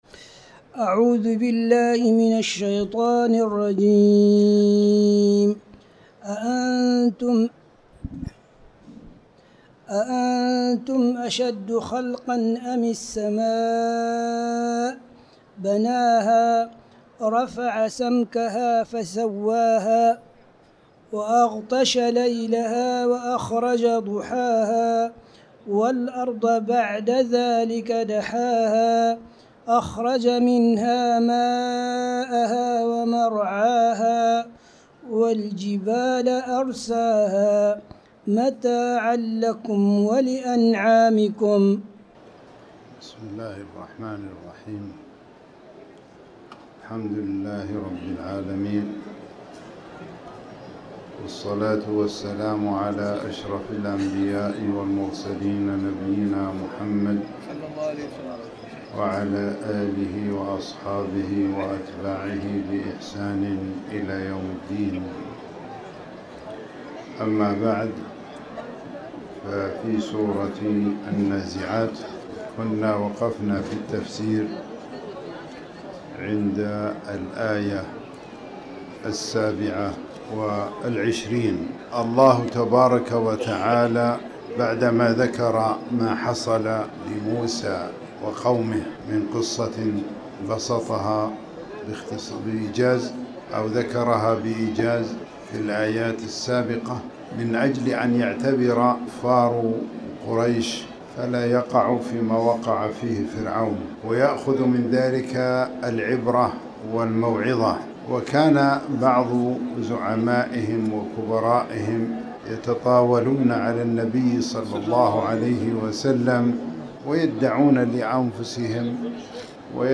تاريخ النشر ١٥ جمادى الأولى ١٤٤٠ هـ المكان: المسجد الحرام الشيخ